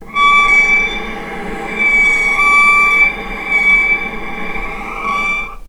vc_sp-D6-mf.AIF